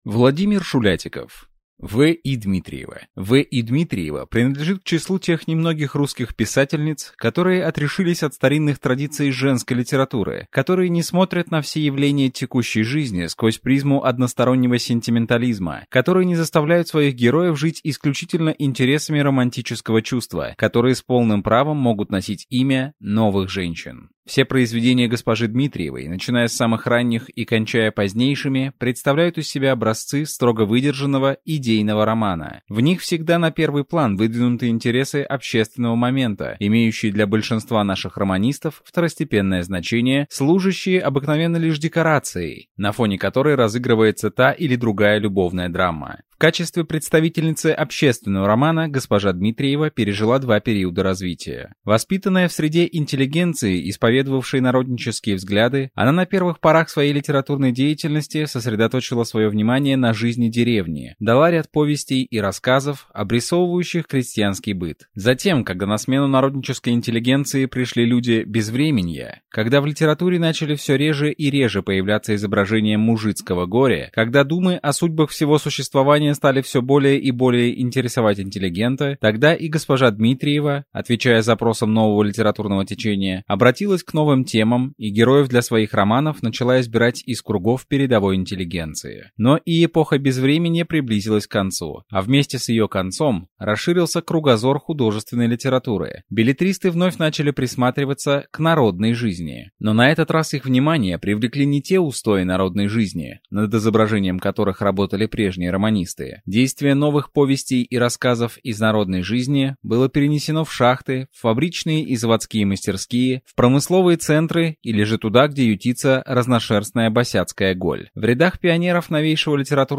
Аудиокнига В. И. Дмитриева | Библиотека аудиокниг